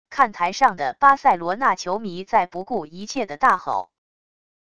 看台上的巴塞罗那球迷在不顾一切的大吼wav音频